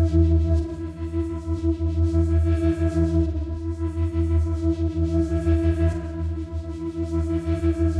Index of /musicradar/dystopian-drone-samples/Tempo Loops/90bpm
DD_TempoDroneB_90-E.wav